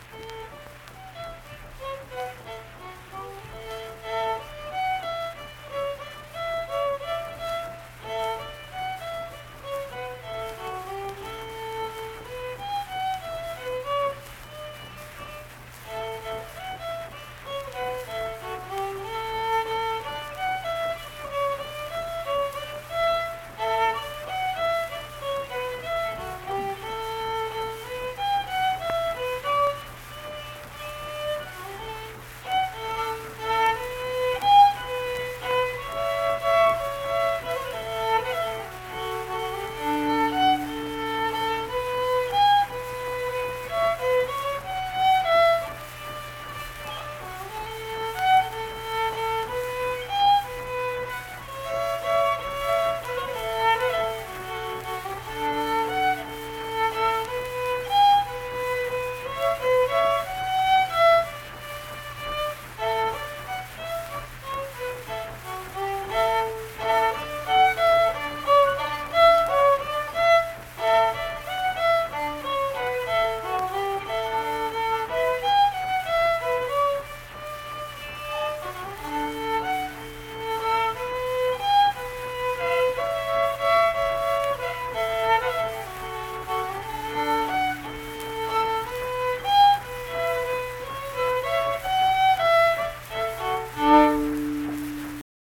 Unaccompanied fiddle music performance
Verse-refrain 2(2).
Instrumental Music
Fiddle